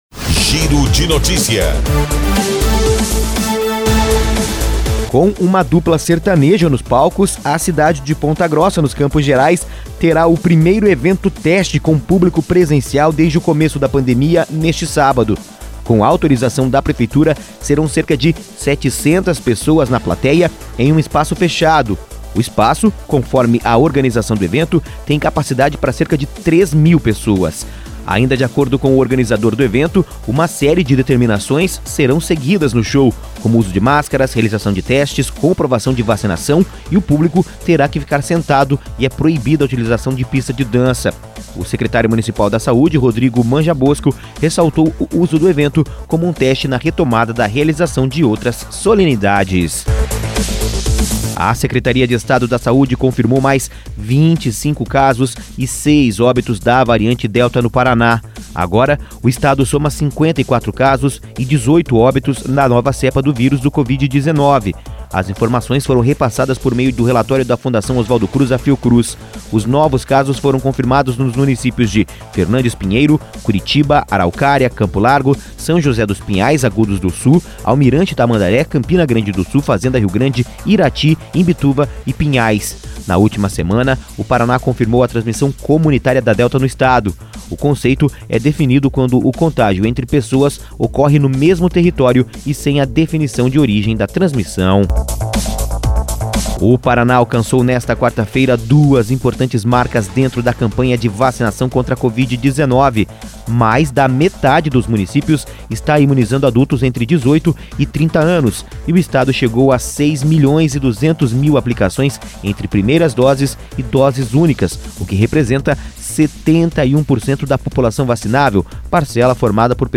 Giro de Notícias – Manhã